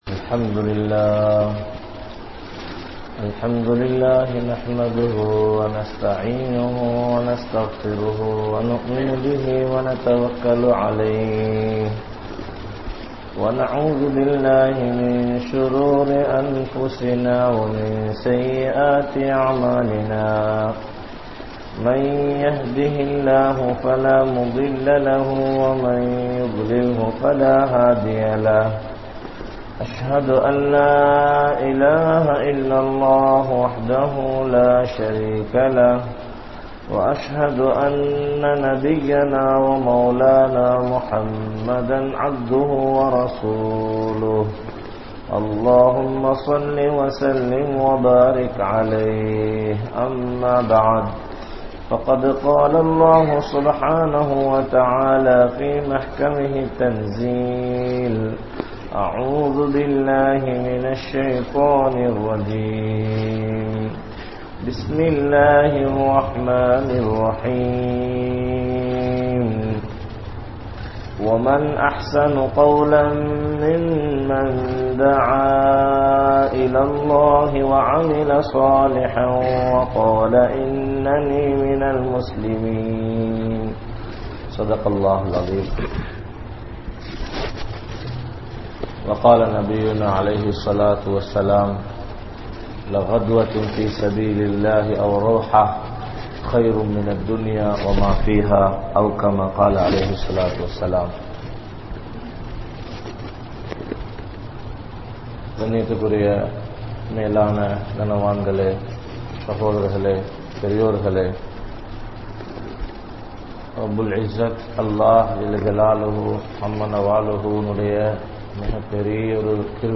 Allah`vin Uthavi Veanduma? (அல்லாஹ்வின் உதவி வேண்டுமா?) | Audio Bayans | All Ceylon Muslim Youth Community | Addalaichenai
Kurunegala, Mallawapitiya, Masjidhul Hasanath